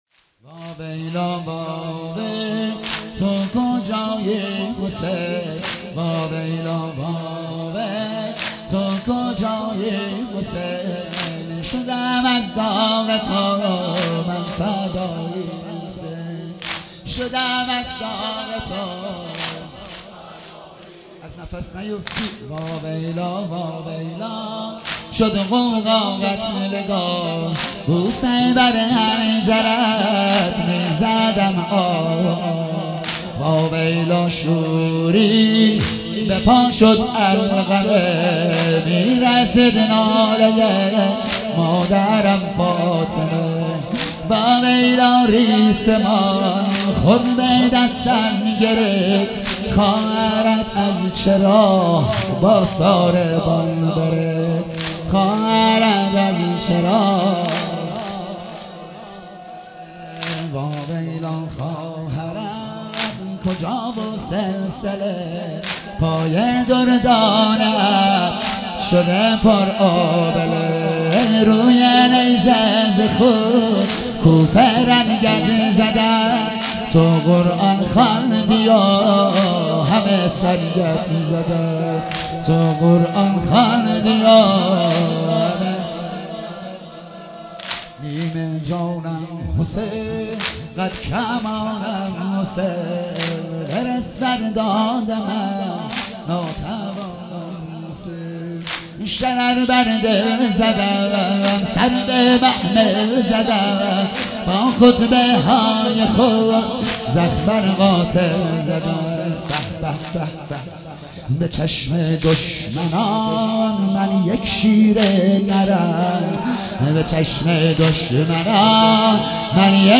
هفتگی 27 مهر - واحد - واویلا واویلا تو کجایی حسین